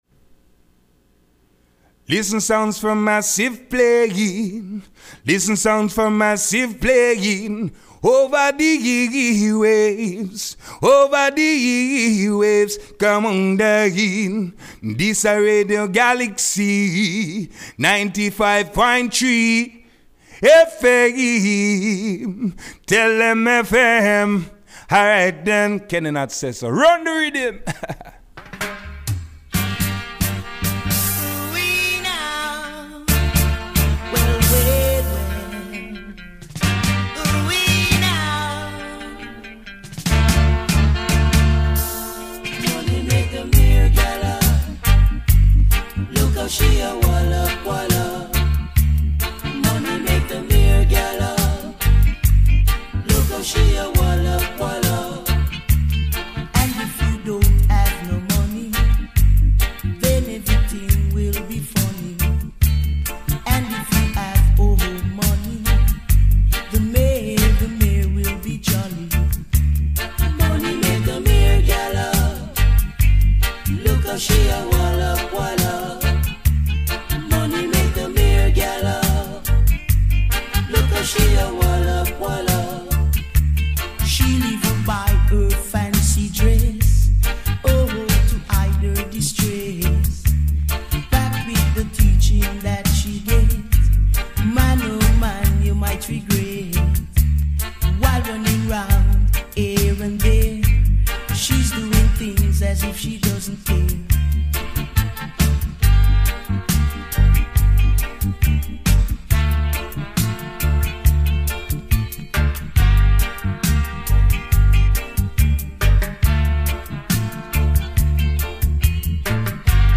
Sound 4 Massive - le REPLAY Retrouvez votre rendez-vous reggaephonique animé par I Bingi Sound et enregistré lundi 15 septembre dans les studios de Galaxie Radio Ecoutez, profitez et partagez